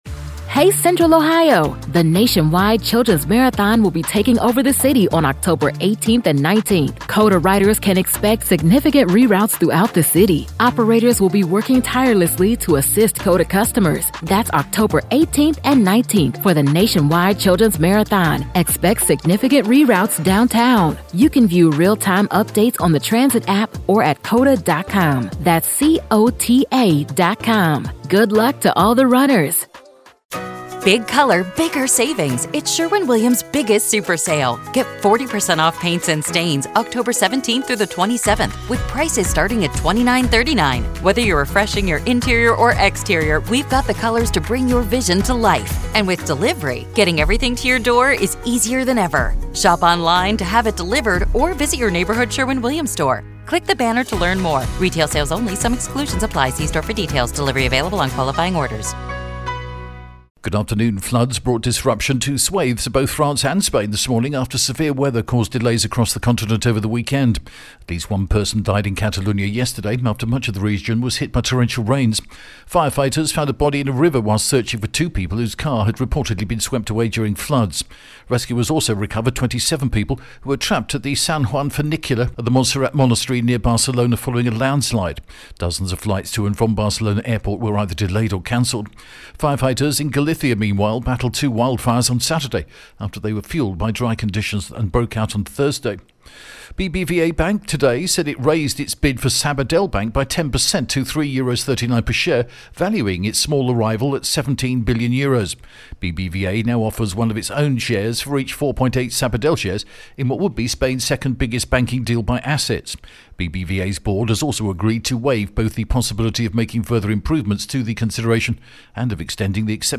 The latest Spanish news headlines in English: September 22nd 2025